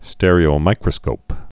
(stĕrē-ō-mīkrə-skōp, stîr-)